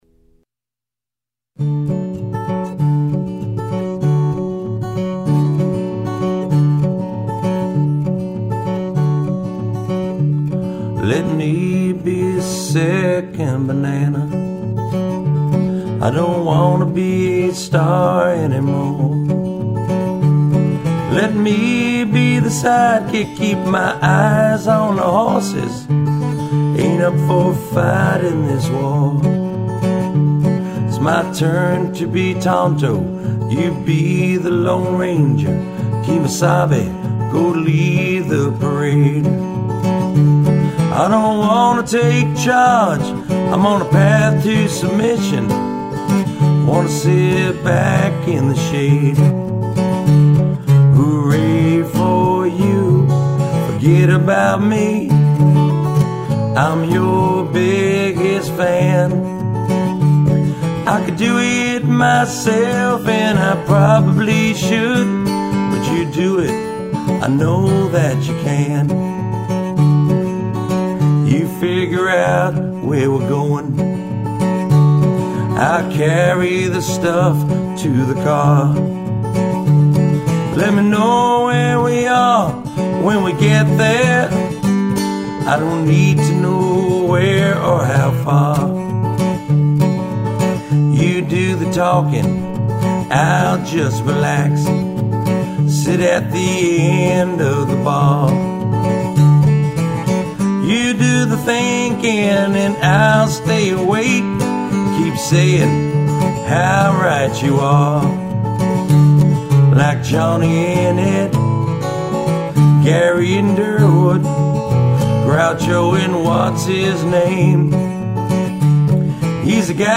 All solo performances